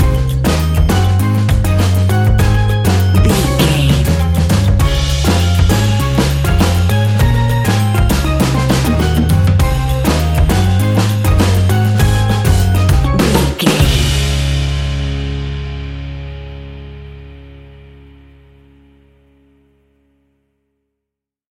Ionian/Major
D♭
calypso
steelpan
happy
drums
percussion
bass
brass
guitar